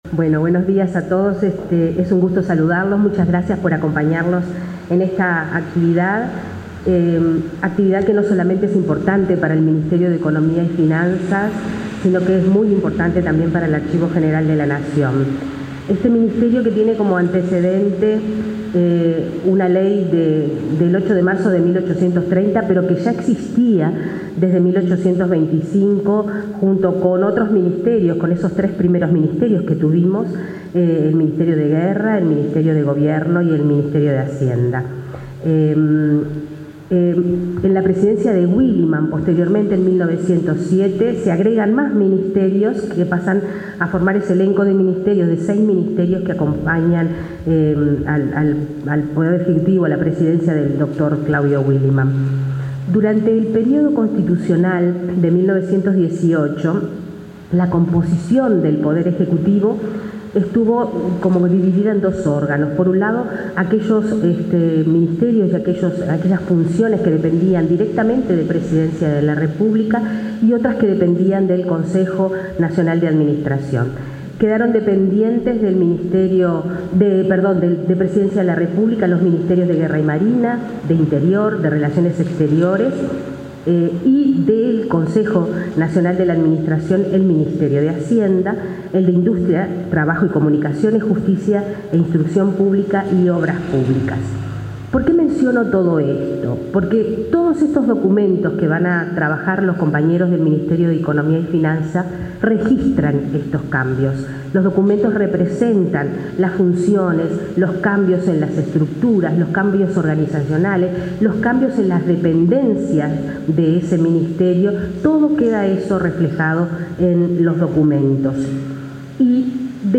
Palabras de autoridades en firma de convenio para digitalizar documentos históricos
El director general del Ministerio de Educación y Cultura, Carlos Varela; su par del de Economía y Finanzas, Gabriela Fachola, y la directora del Archivo General de la Nación, Alejandra Villar, participaron en la firma del convenio para la digitalización de documentos históricos del período 1825-1911 pertenecientes al Ministerio de Hacienda del Uruguay, hoy de Economía y Finanzas.